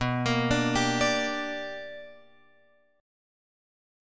B Minor Major 7th Sharp 5th